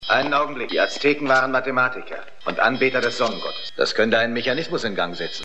Lex Barker: Dr. Karl Sternau,  Synchronschauspieler: Gert Günther Hoffmann
Hörprobe des deutschen Synchronschauspielers (86 Kb)